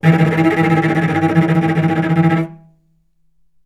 vc_trm-E3-mf.aif